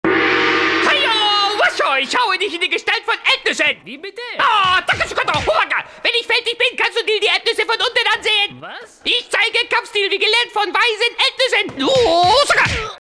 deutscher Sprecher
Sprechprobe: Sonstiges (Muttersprache):
german male voice over